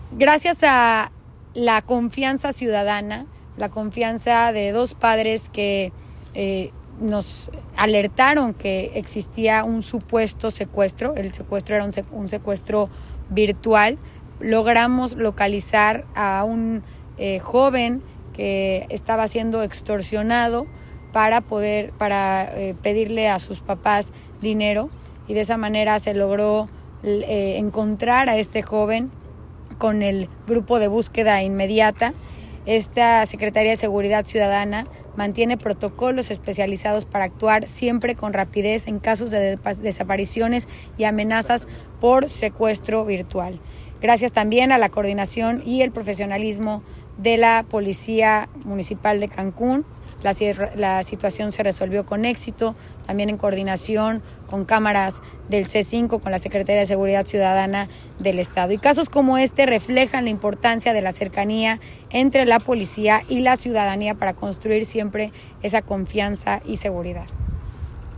Cancún.- La Presidenta Municipal Ana Paty Peralta informó en entrevista, que gracias a una rápida reacción y a la aplicación de protocolos del Agrupamiento Especializado en Búsqueda de Personas No Localizadas, de la Secretaría Municipal de Seguridad Ciudadana y Tránsito (SMSCyT) de Benito Juárez, se localizó sano y salvo un joven reportado como desaparecido, tras recibirse una denuncia vinculada a una extorsión telefónica.
Entrevista-APP-Localización-de-menor-víctima-de-secuestro-virtual.m4a